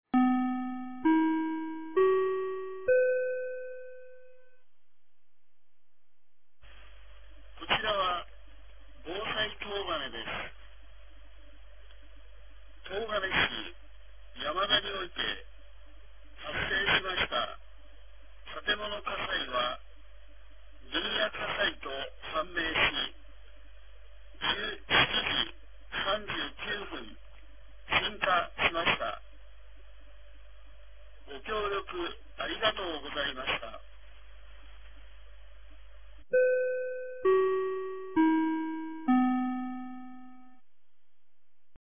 2024年12月22日 17時42分に、東金市より防災行政無線の放送を行いました。